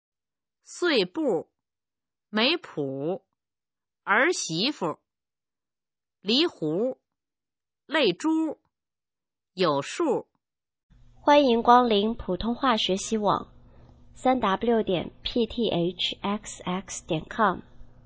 普通话水平测试用儿化词语表示范读音第17部分